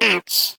Sfx_tool_spypenguin_vo_hit_wall_16.ogg